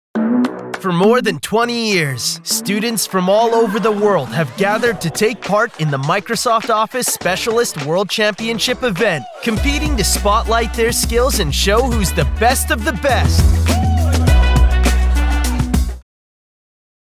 Microfone: Neumann U87ai
Tratamento acústico: Broadcast Studio Room